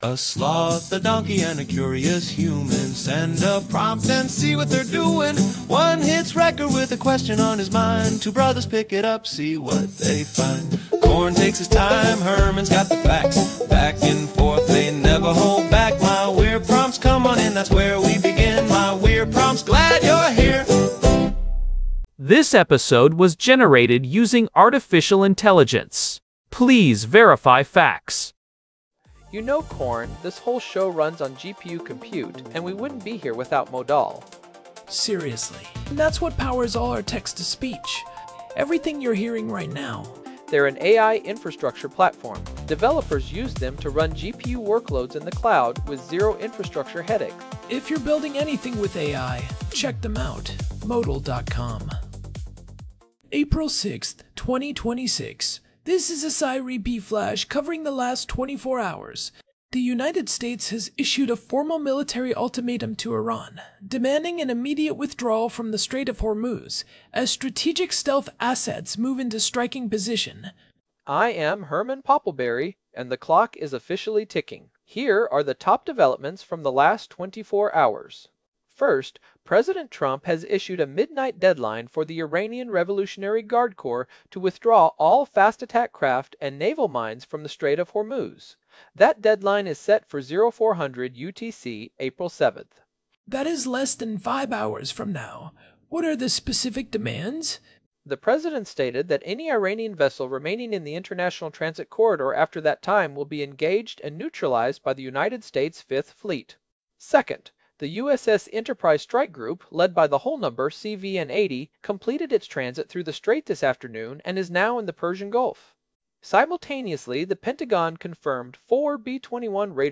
TTS Engine
chatterbox-regular
AI-Generated Content: This podcast is created using AI personas.